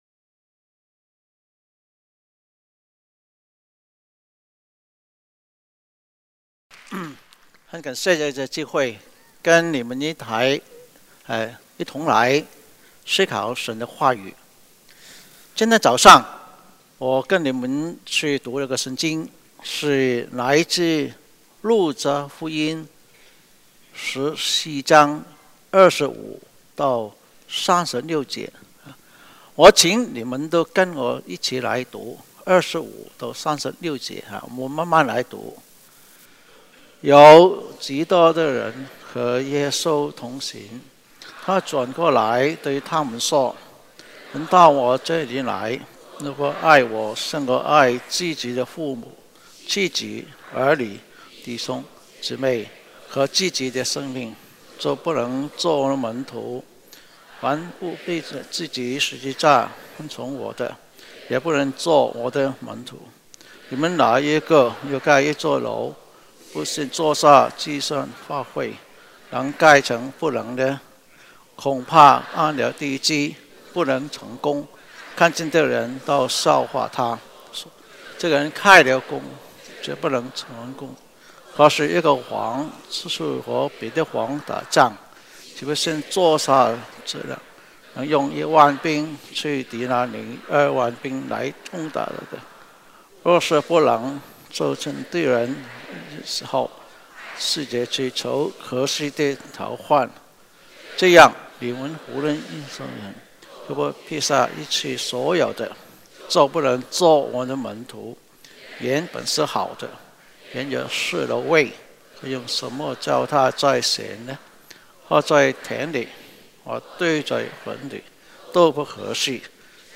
主日证道 | 计算的代价